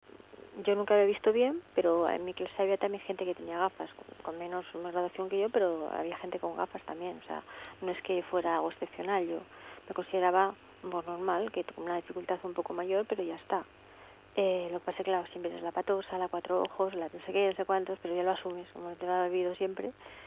suena cercana, increíblemente dulce, aniñada, con colores de tonos suaves, casi infantiles.